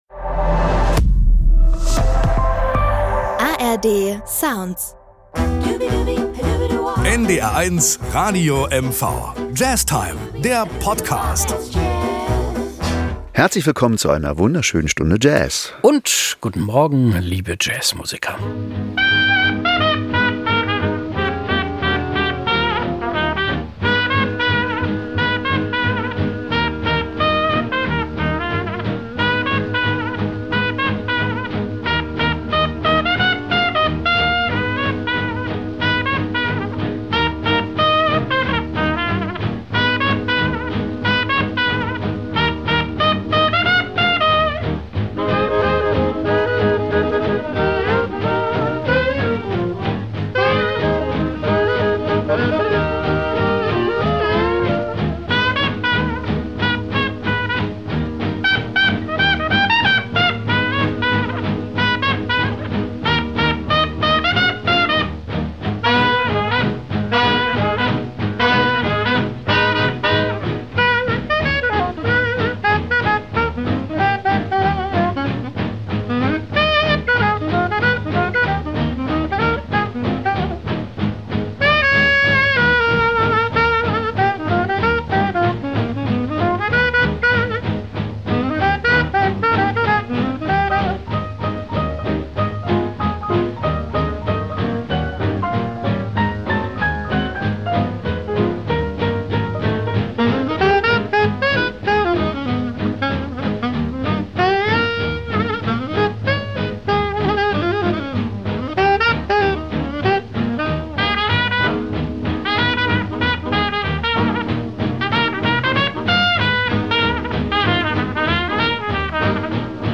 Das LIVE Anspiel diesmal ist: “Satin Doll“ – 1953 vom „Duke“ und Billy Strayhorn komponiert.